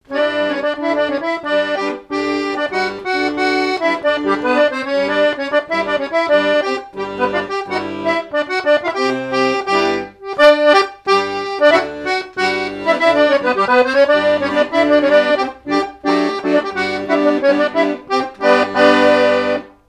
danse : scottich trois pas
airs de danses issus de groupes folkloriques locaux
Pièce musicale inédite